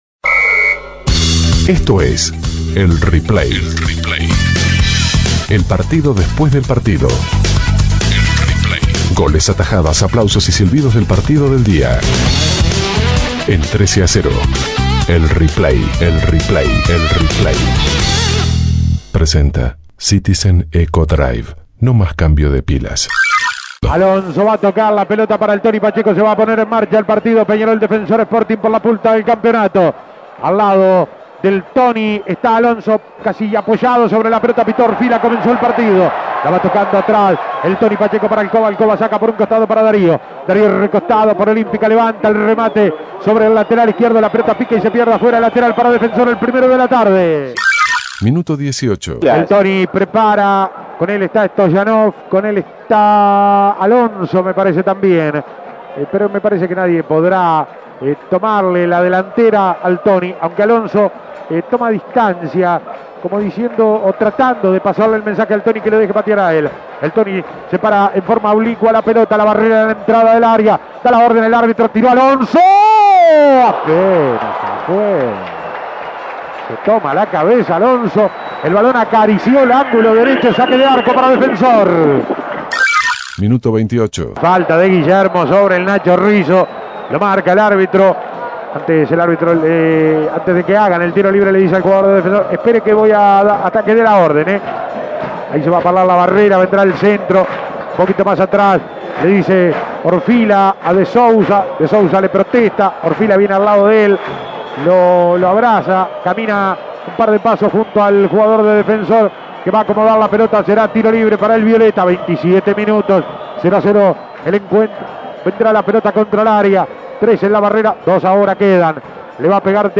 Goles y comentarios Escuche el replay de Peñarol - Defensor Imprimir A- A A+ Defensor logró una importantísima victoria que lo acerca al campeonato Apertura.